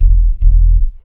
BASS 1 115-L.wav